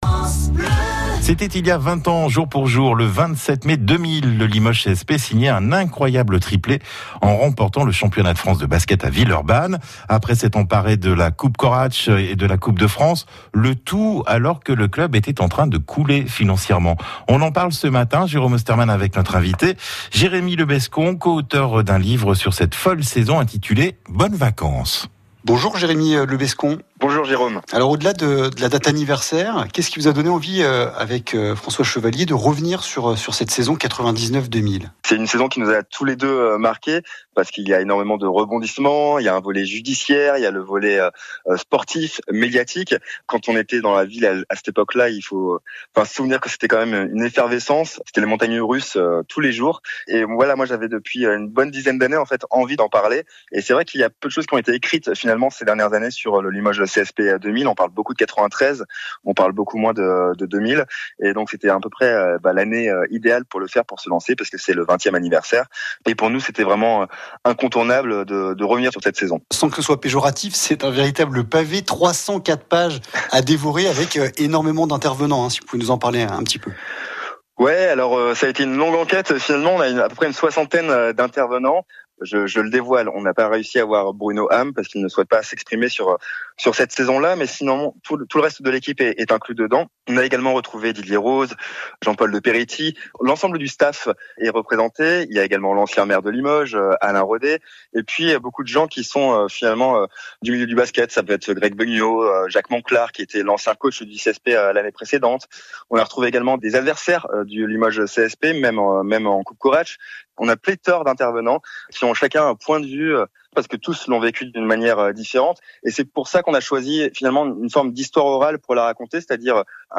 ITW – France Bleu Limousin